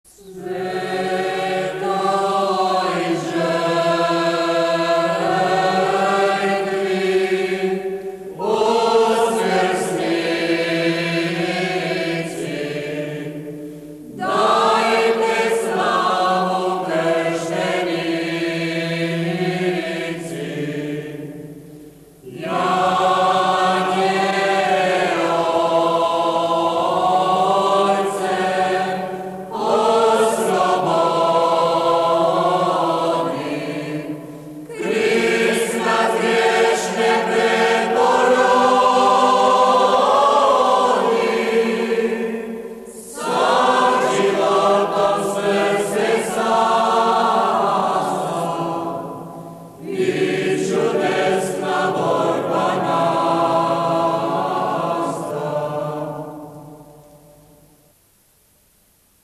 Otok Hvar - Za križen 5 - Jelsa | Delmata